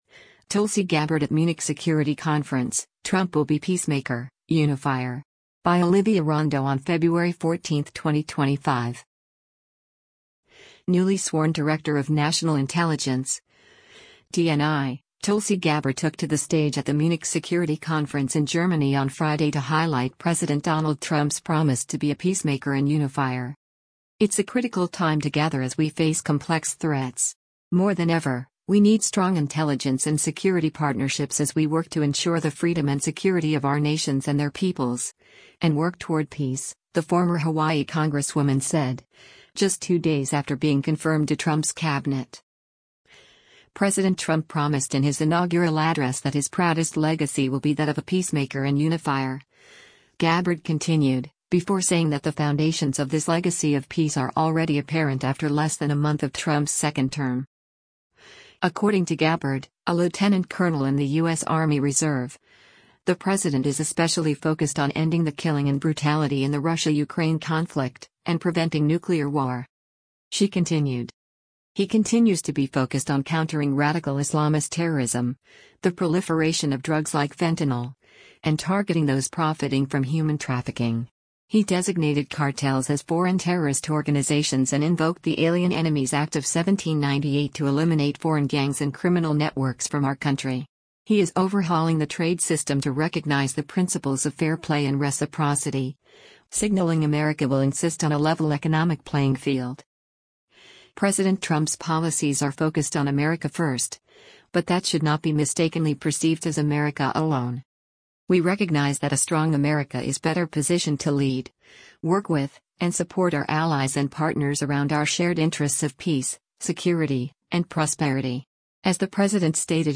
Tulsi Gabbard at Munich Security Conference: Trump Will Be 'Peacemaker'
Newly-sworn Director of National Intelligence (DNI) Tulsi Gabbard took to the stage at the Munich Security Conference in Germany on Friday to highlight President Donald Trump’s promise to be a “peacemaker and unifier.”